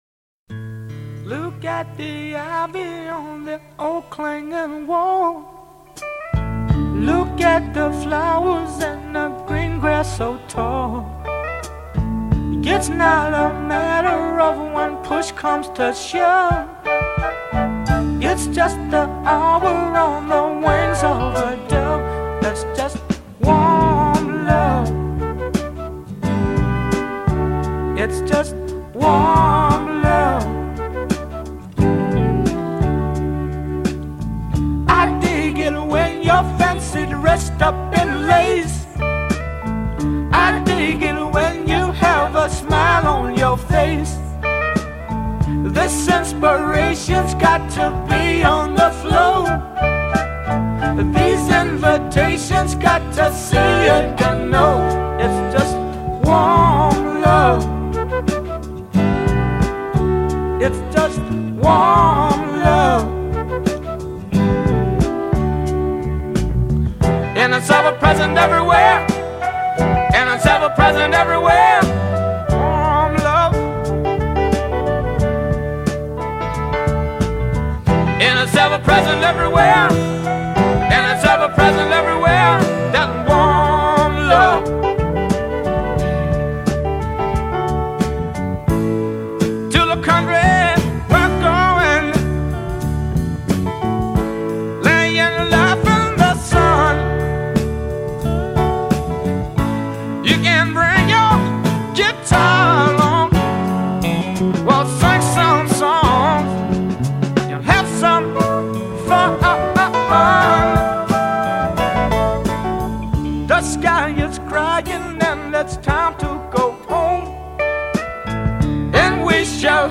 Blues Rock, Soul